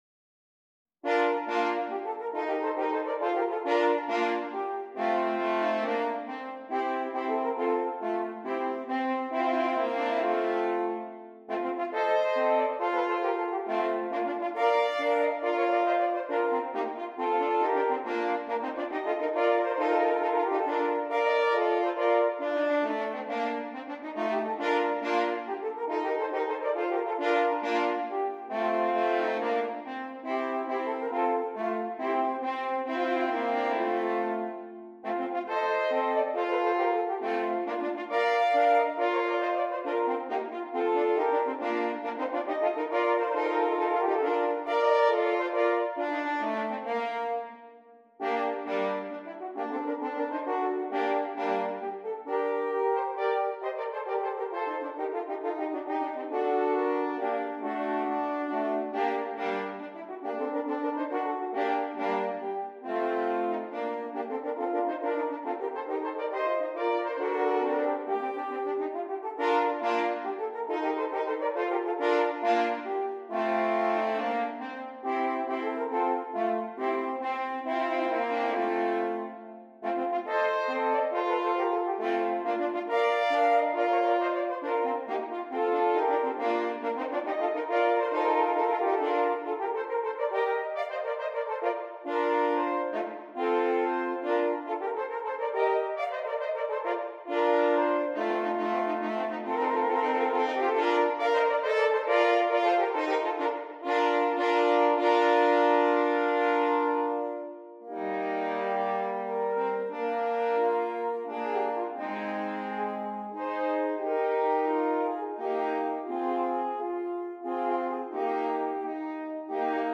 3 F Horns